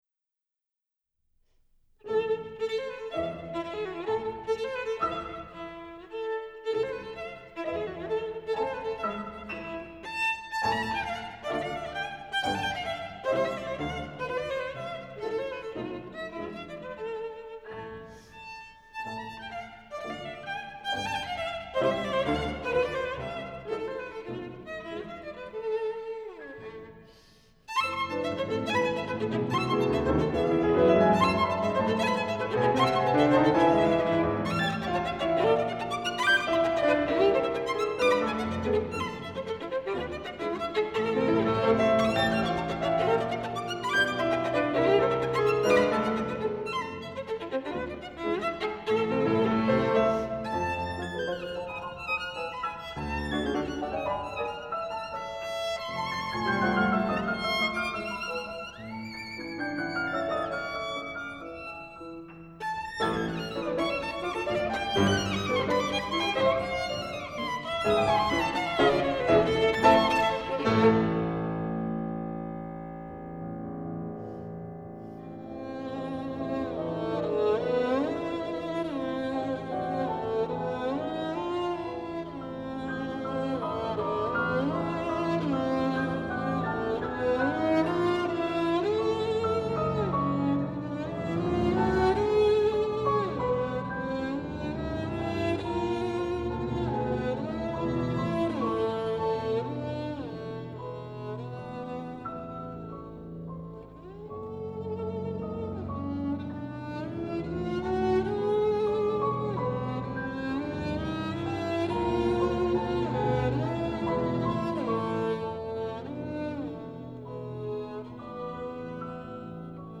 ヴァイオリン
この独特な音色は、重厚にして繊細な演奏により。